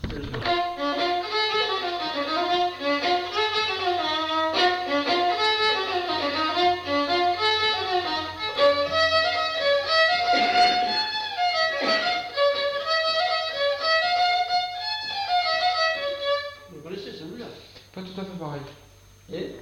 Aire culturelle : Petites-Landes
Lieu : Roquefort
Genre : morceau instrumental
Instrument de musique : violon
Danse : rondeau
Ecouter-voir : archives sonores en ligne